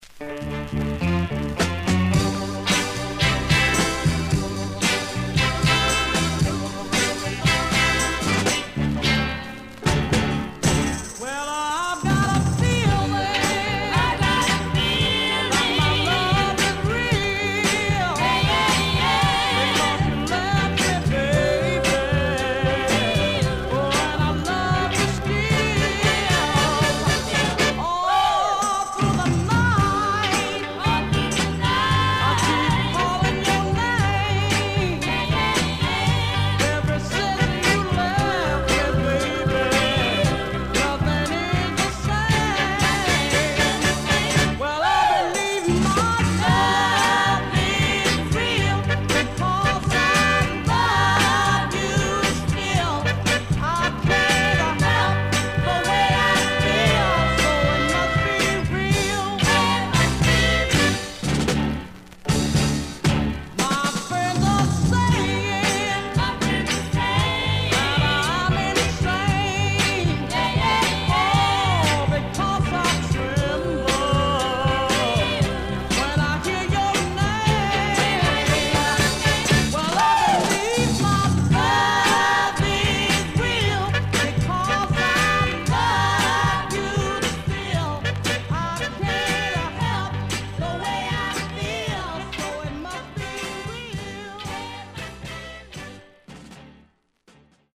Surface noise/wear
Mono
Soul